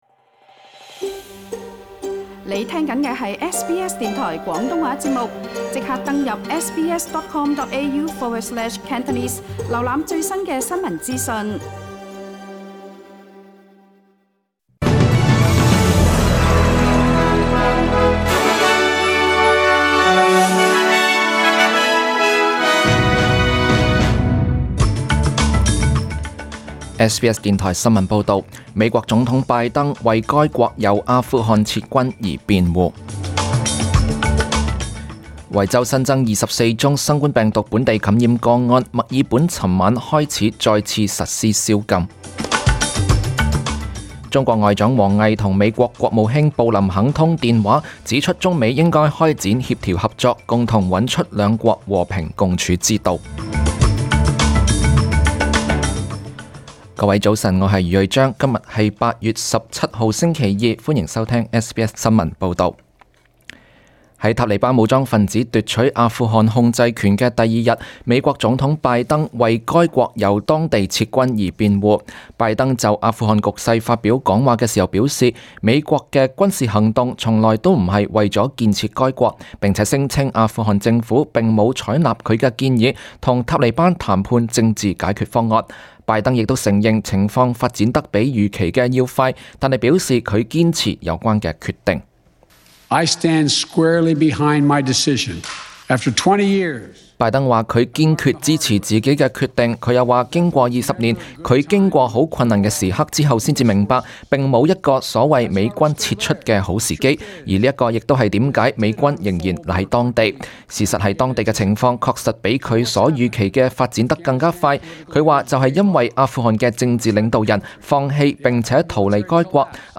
SBS 廣東話節目中文新聞 Source: SBS Cantonese